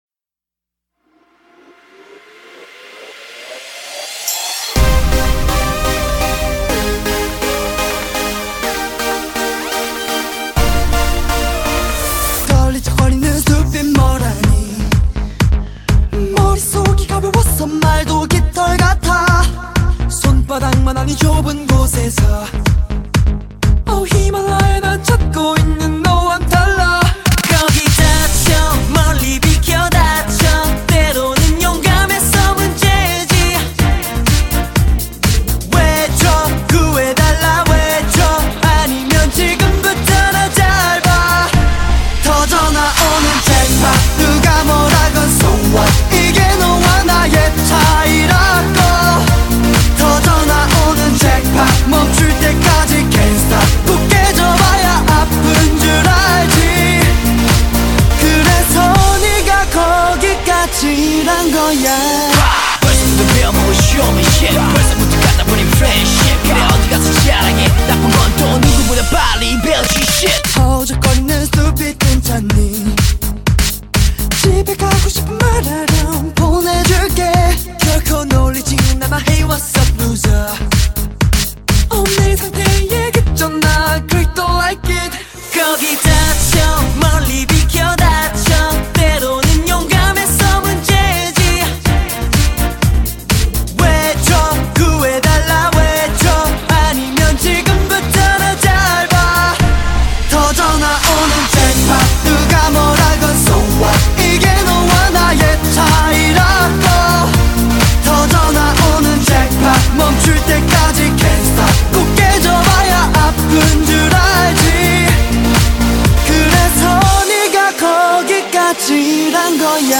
хочу вам дать послушать корейскую попсу